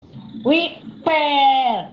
Famosito Wipe Sound Effect